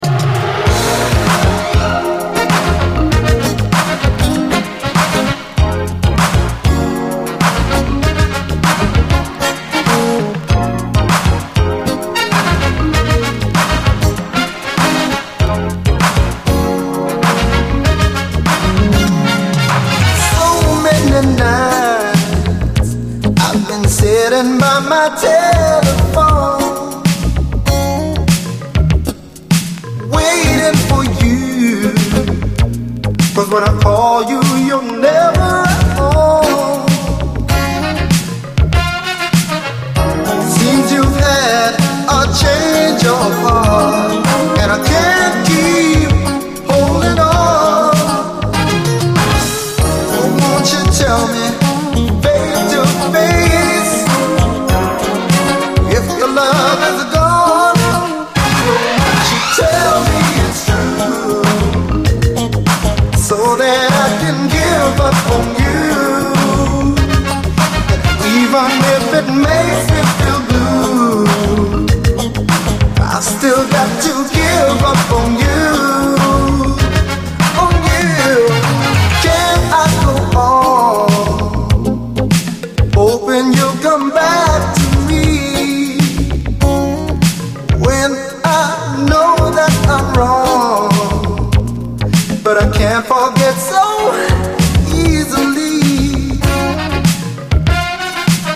SOUL, 70's～ SOUL, DISCO, 7INCH
UK産80’Sアーバン・シンセ・ソウル！